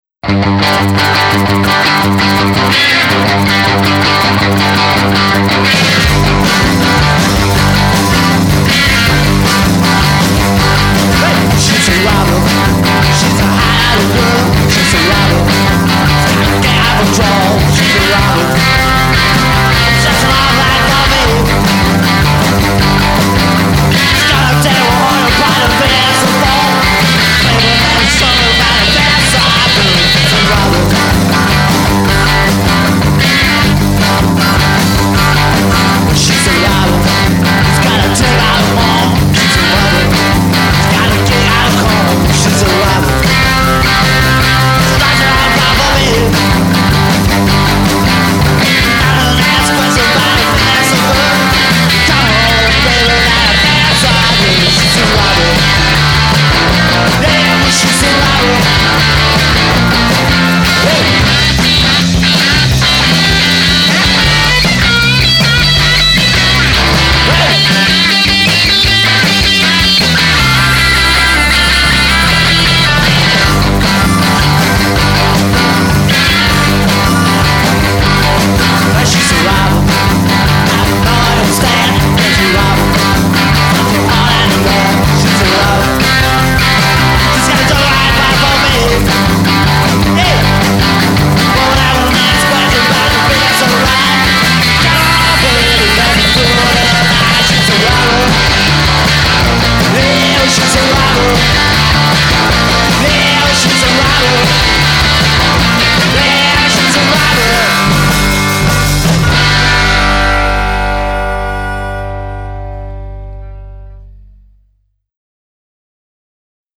Pub Punk/Garage Rock style
Vocals & Harmonica
Guitar & Backing vocals
Bass & Piano